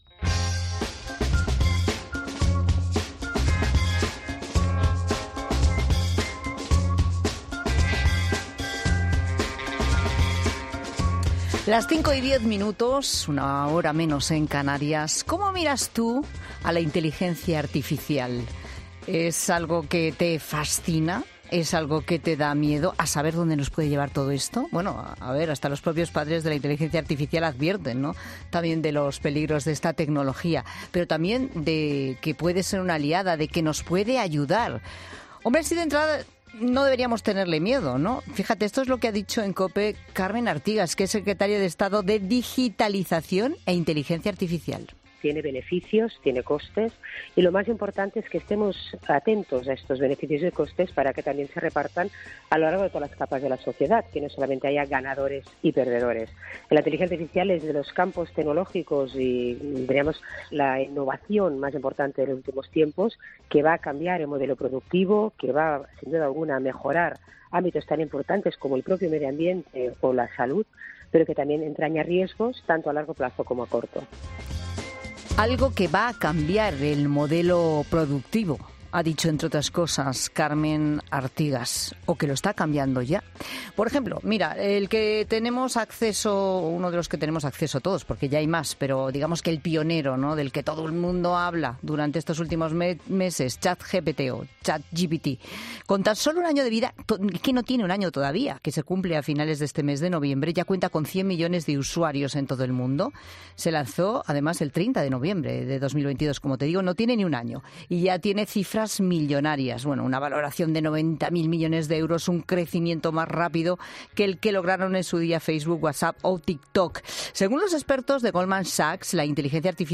AUDIO: El economista ha pasado por 'La Tarde' para explicar cómo la inteligencia artificial puede ayudarte a mejorar un negocio o una empresa